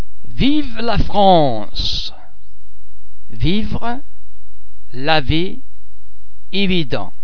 The French [v] and [w] are normally pronounced [v] as in the English words victory, savage, veal etc.
v - as